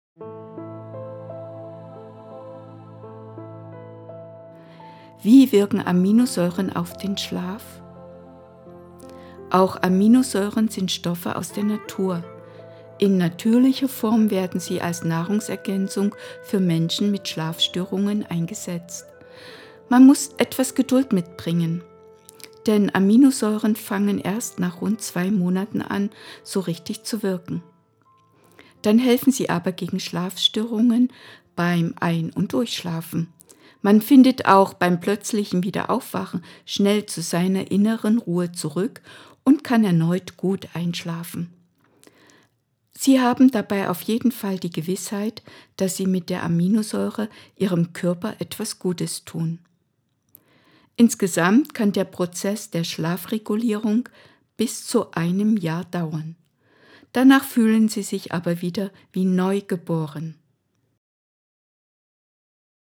Einschlaftipps zum Hören - das Hörbuch: